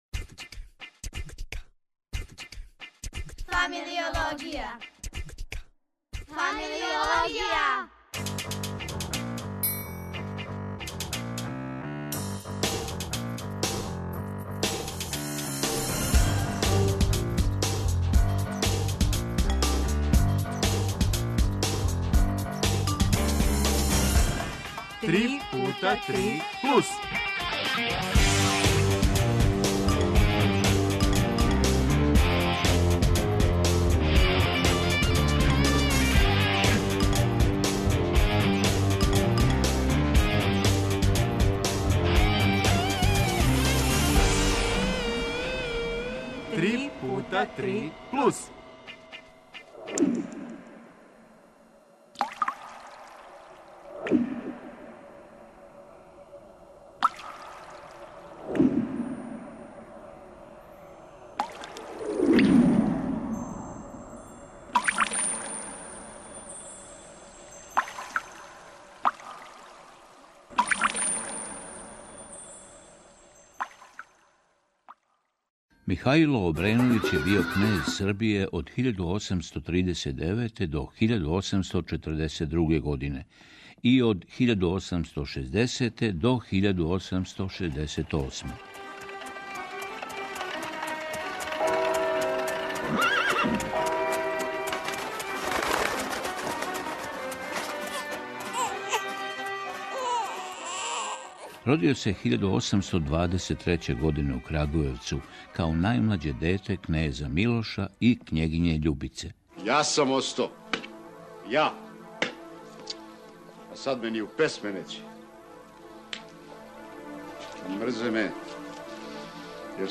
Гости су нам тројица студената Медицинског факултета у Београду који баш данас уписују четврту годину, а управо су се вратили с Евопског биомедицинског конгреса науке.